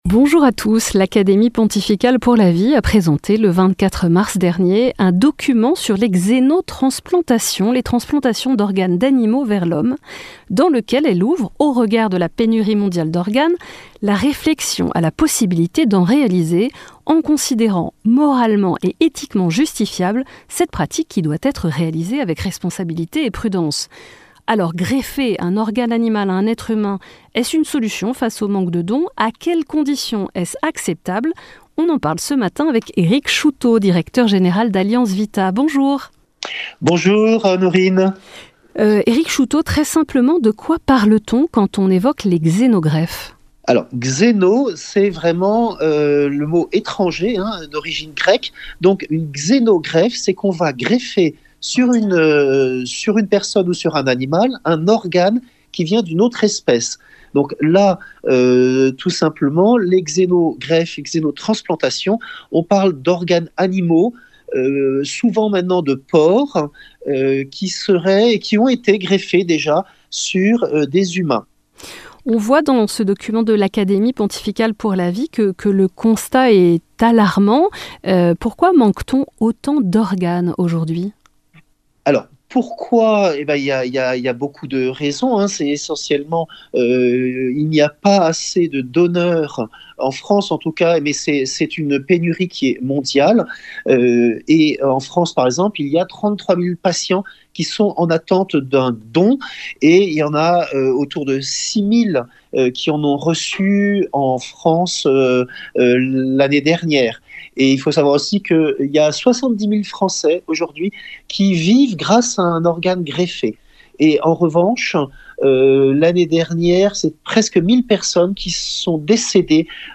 Accueil \ Emissions \ Information \ Régionale \ Le grand entretien \ Greffer un organe animal à un être humain : est-ce une solution face au (…)